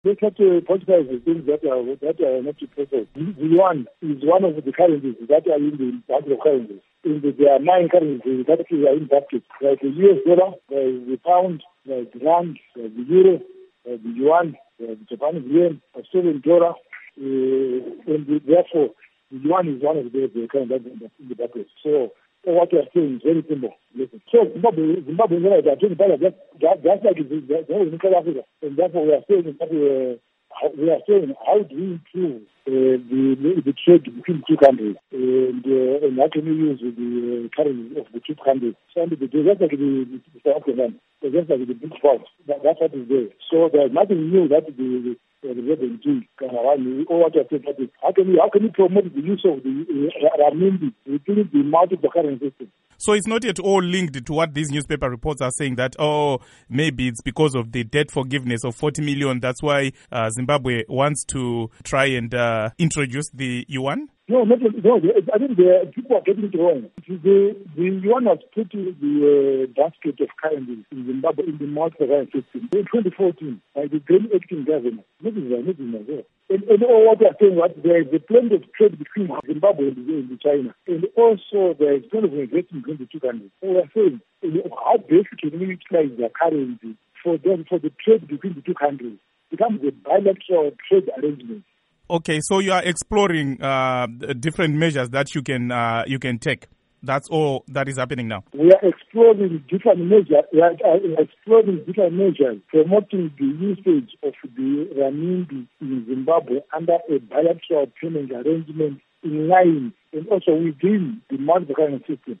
Interview With John Mangudya on Chinese Yuan